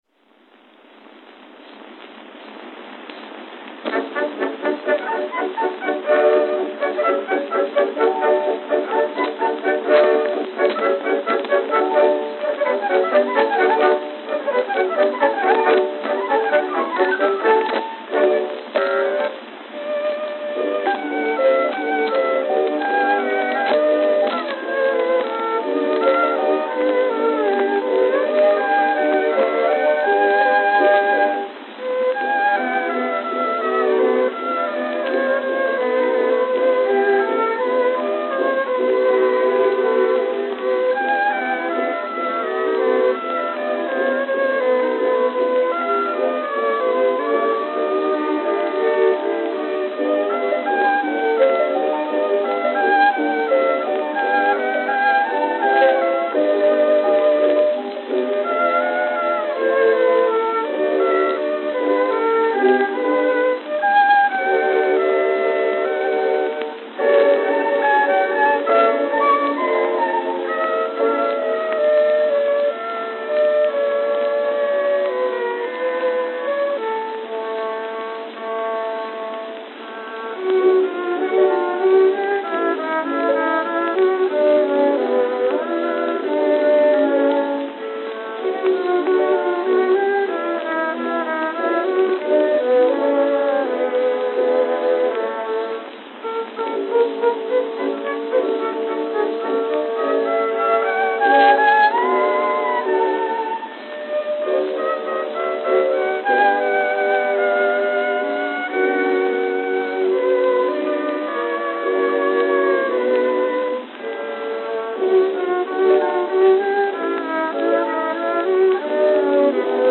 Recital
(dubbed from earlier recordings)
Note: Lamination crack on edge, audible until 0:45.